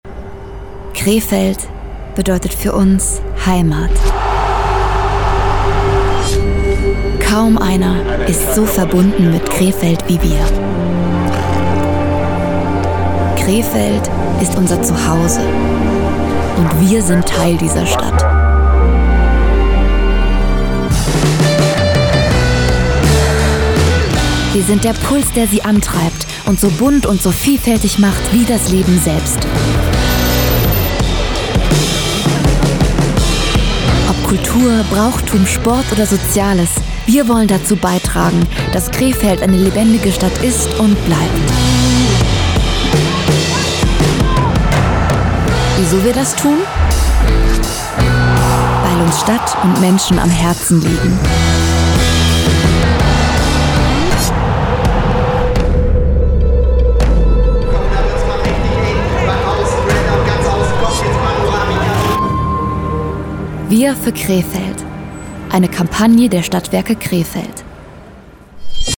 Sprecherin, Werbesprecherin